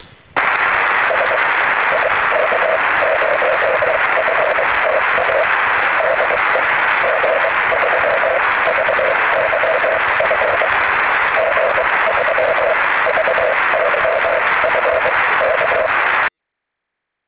UNUSUAL AURORA IN JN61 SQUARE!